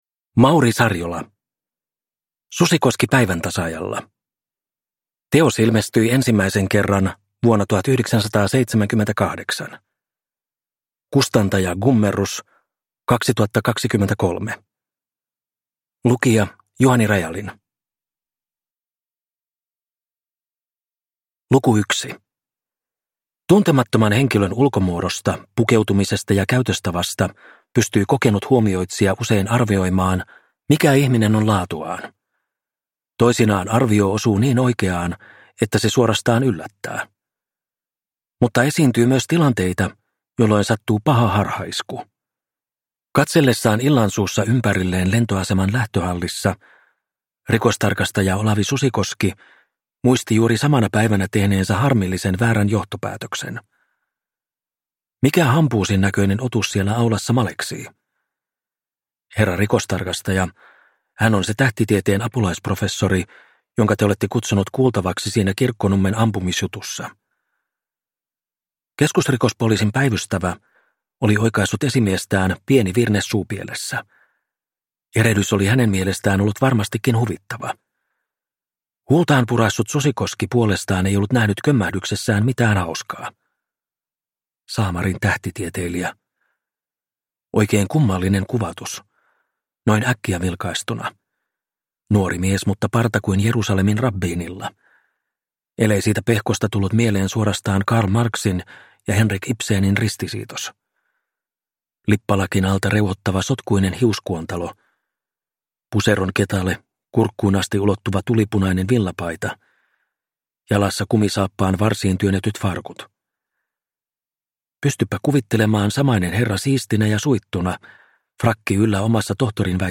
Susikoski päiväntasaajalla – Ljudbok – Laddas ner